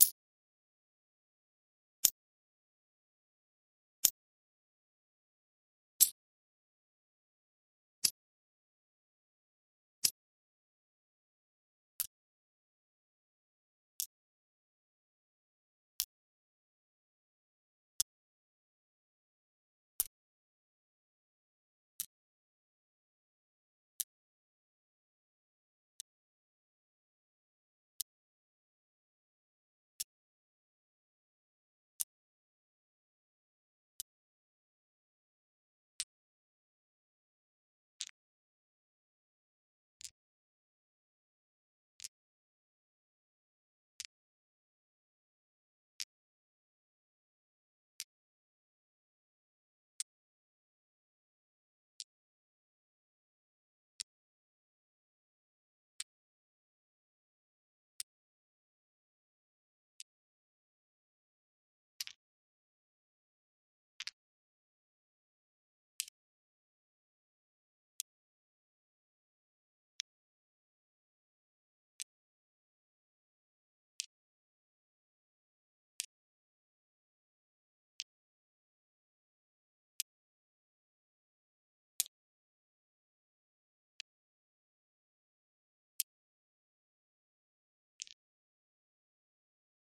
dicehit.mp3